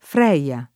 vai all'elenco alfabetico delle voci ingrandisci il carattere 100% rimpicciolisci il carattere stampa invia tramite posta elettronica codividi su Facebook Freyja [ted. fr # i L a ] o Freia [ fr # ia ] o Freya [ fr # ia ] pers. f. mit. — come nome di donna, in it. Freya [ fr $L a ] o (meglio) Freia [ id. ]